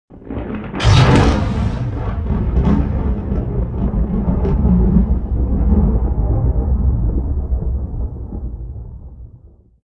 lightning1.wav